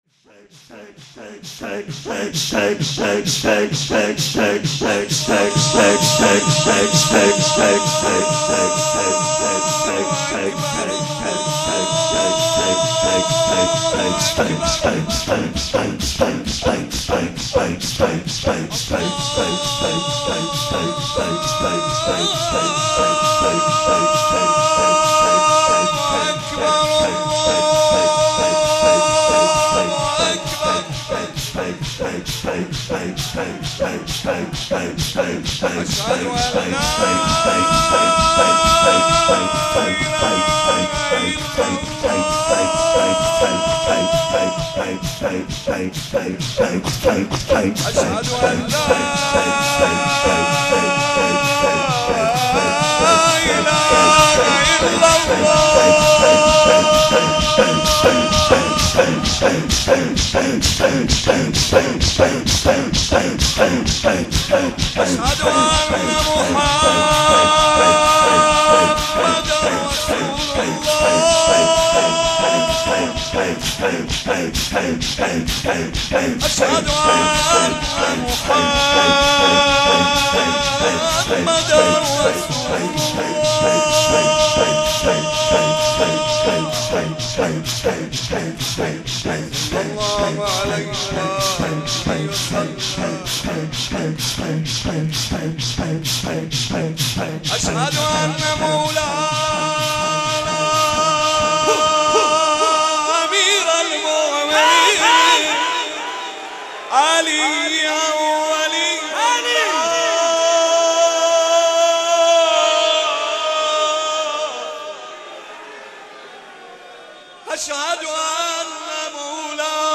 شور، زمزمه